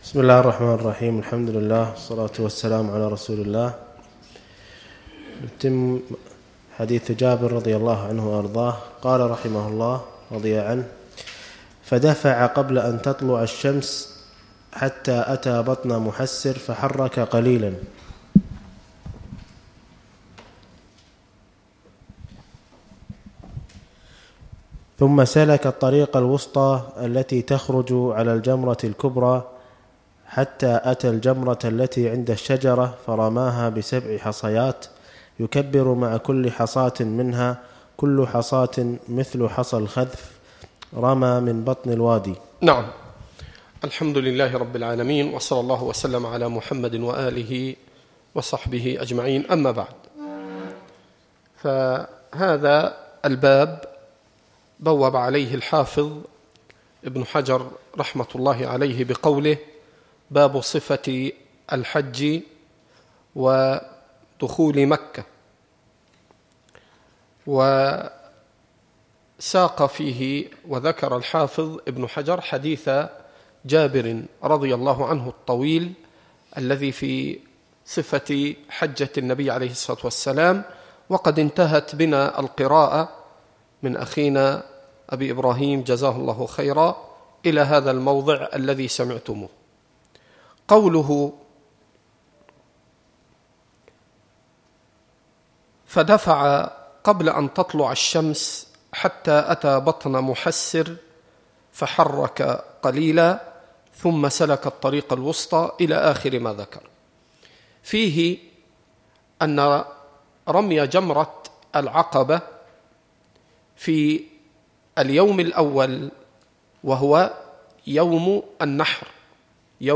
الدروس والمحاضرات